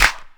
JJClap (26).wav